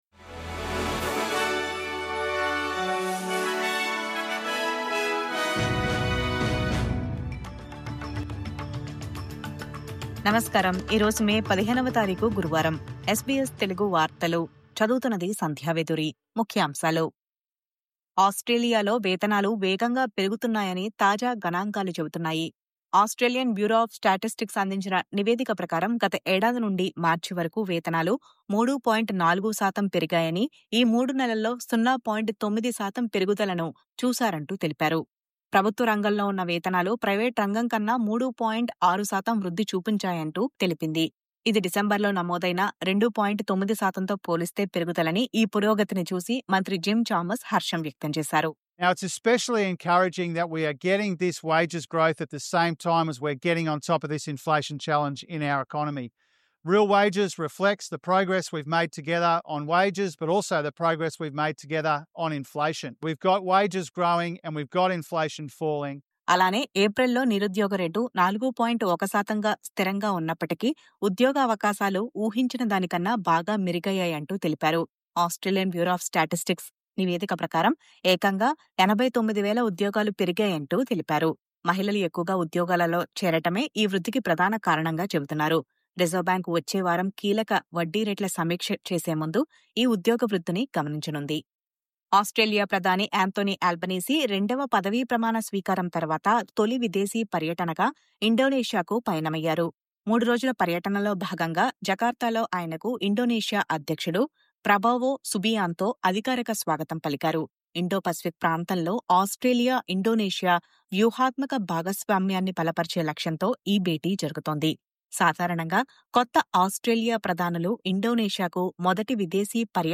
SBS తెలుగు వార్తలు..